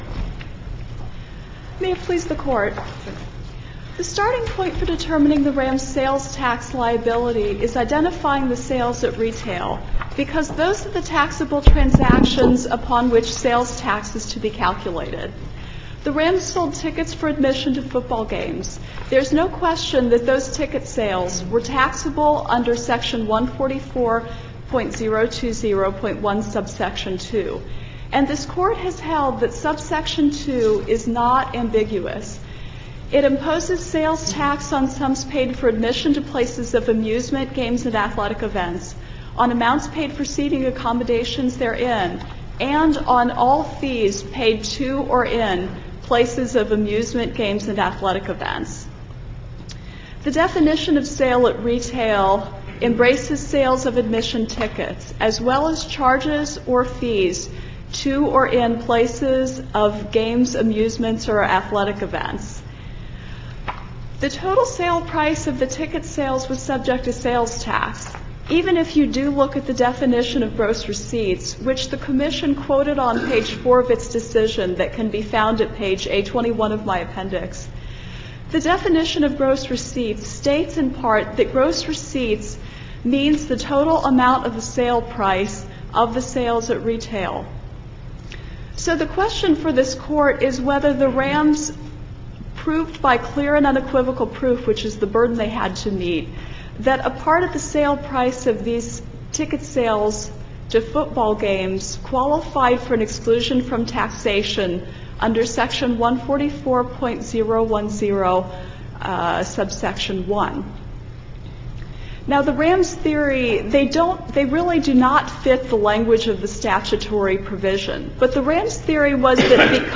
MP3 audio file of oral arguments in SC95910